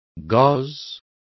Complete with pronunciation of the translation of gauzes.